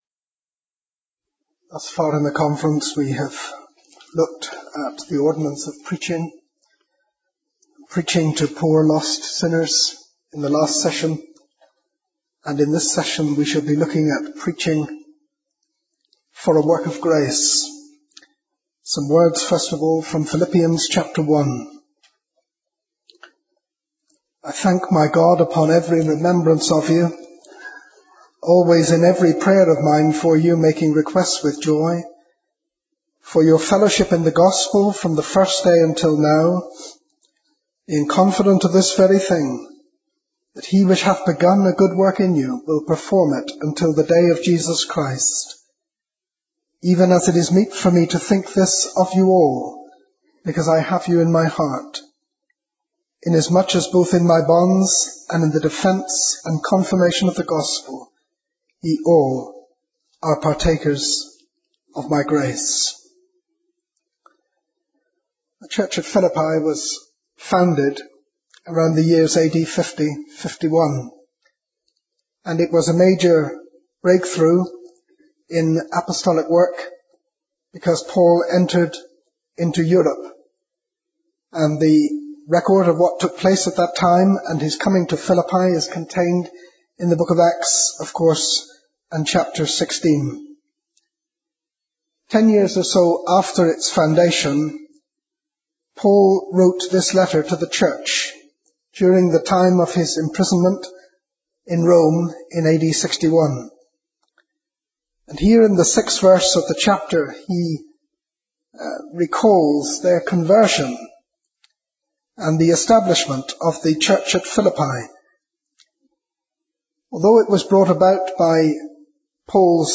Sermons | Grace Minister's Conference